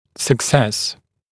[sək’ses][сэк’сэс]успех, благоприятный исход